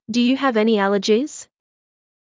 ﾄﾞｩ ﾕｰ ﾊﾌﾞ ｴﾆｰ ｱﾗｼﾞｰｽﾞ